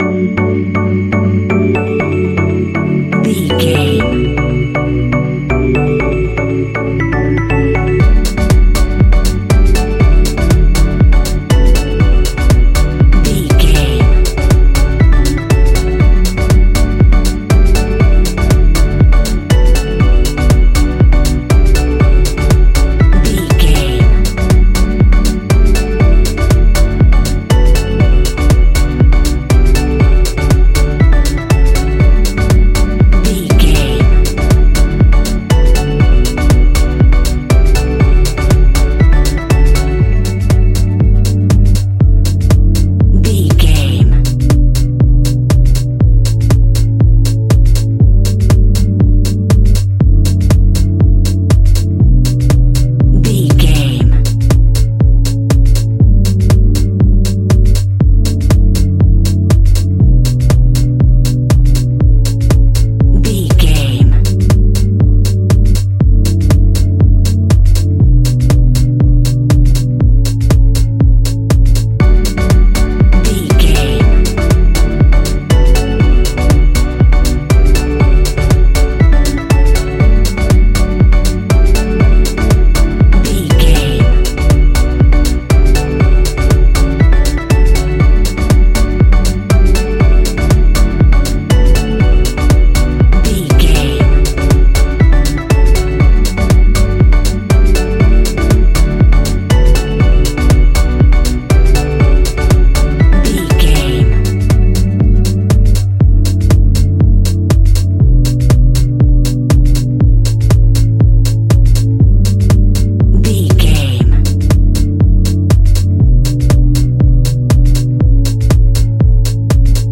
Ionian/Major
E♭
house
electro dance
synths
techno
trance